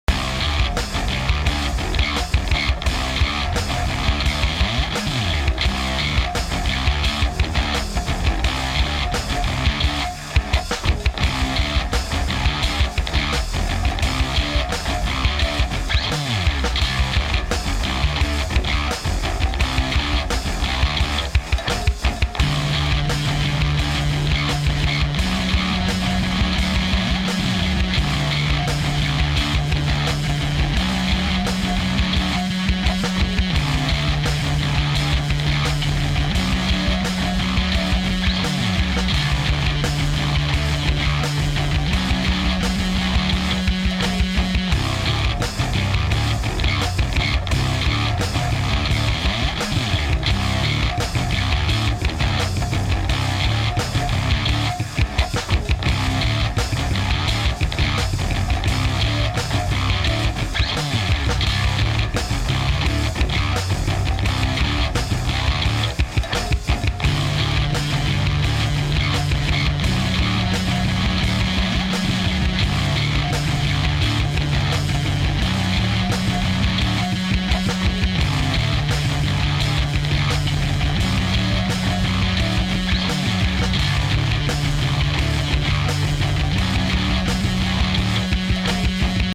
home of the daily improvised booty and machines -
orginal 3 bass grooves